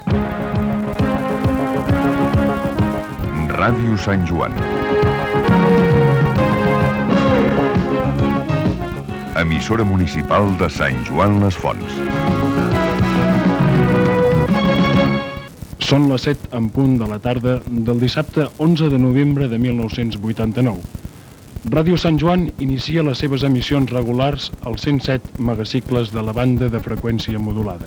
Indiciatiu i inici de la primera emissió.
FM